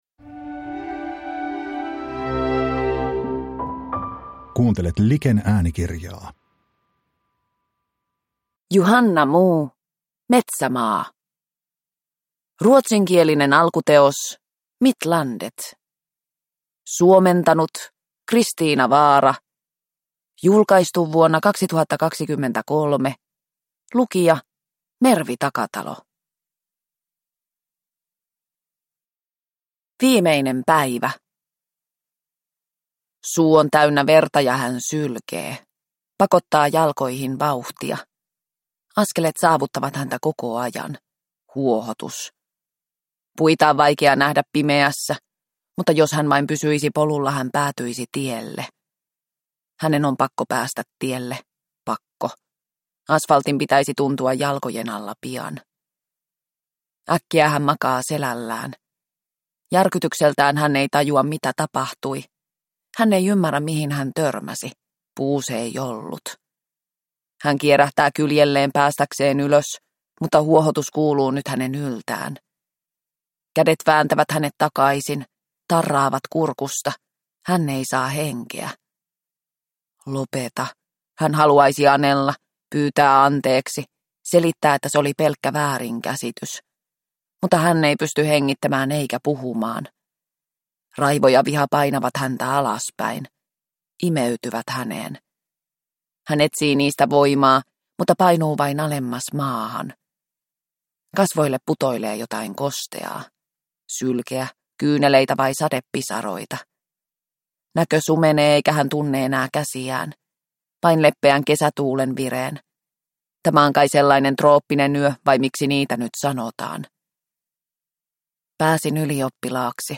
Metsämaa – Ljudbok – Laddas ner